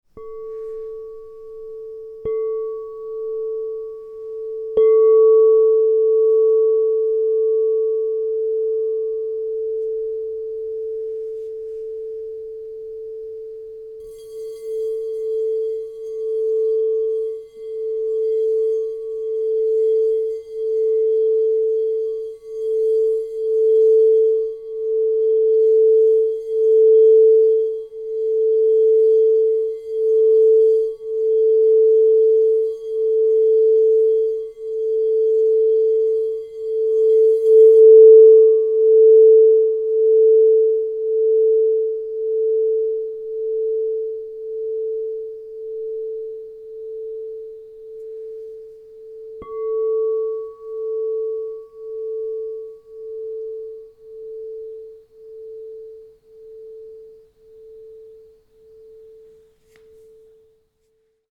Tesseract Salt 6″ B +20 Crystal Tones® Singing Bowl